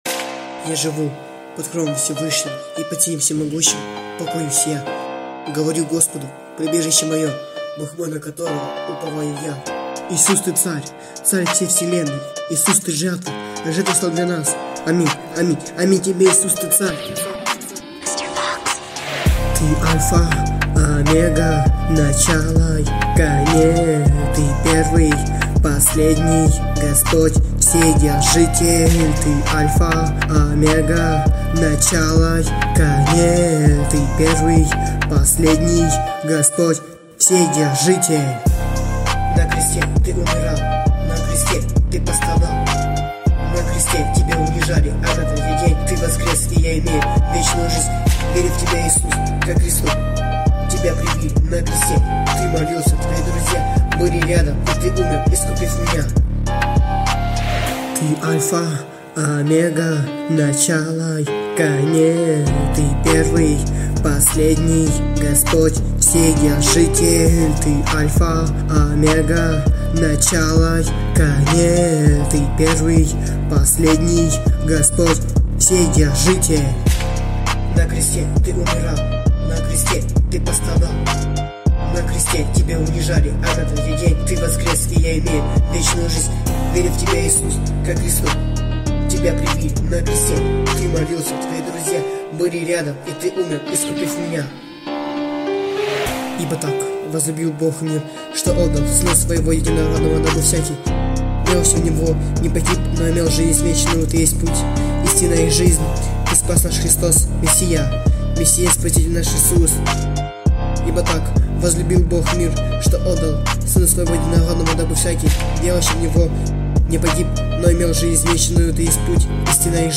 383 просмотра 469 прослушиваний 24 скачивания BPM: 101